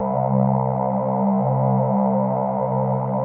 Index of /90_sSampleCDs/Wizoo - Powered Wave/PPG CHOIR